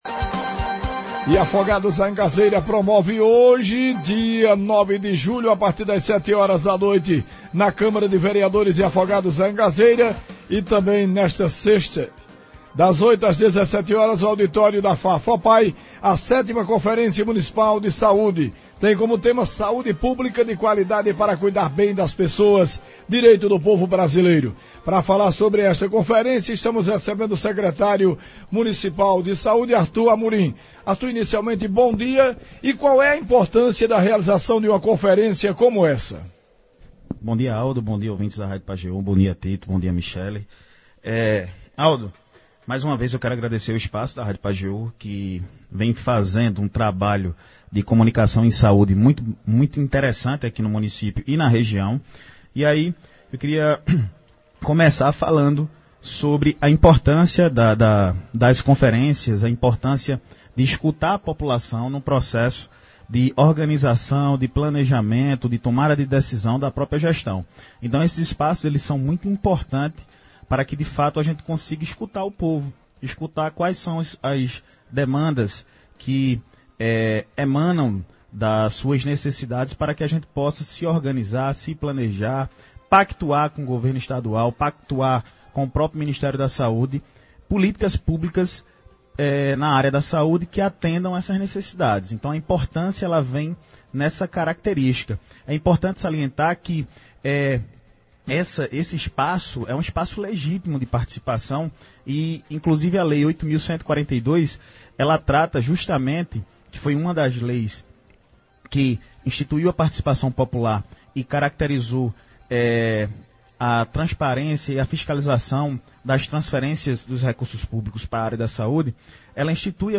Mais cedo nos estúdios da Pajeú o secretário de saúde do Município Artur Belarmino, falou sobre a conferência e convidou a população para participar. Artur também aproveitou a ocasião para responder questionamentos de ouvintes e internautas.